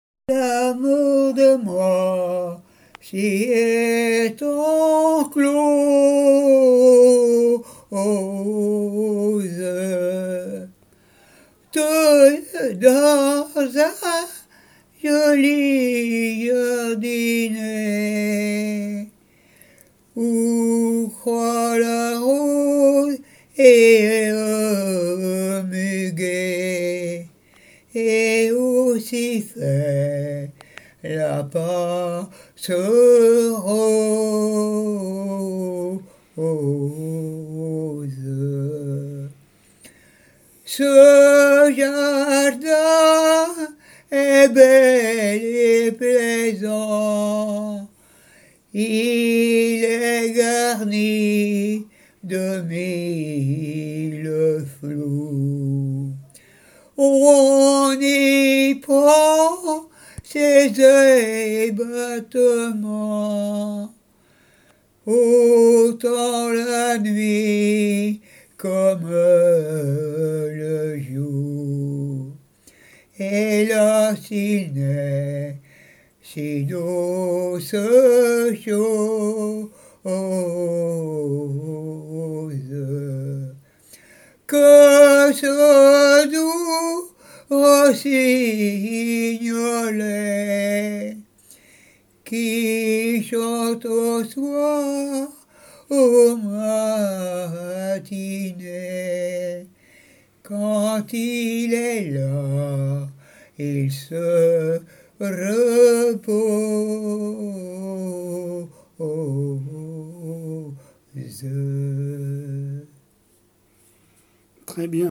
Jard-sur-Mer
Genre strophique
chansons traditionnelles et d'école
Pièce musicale inédite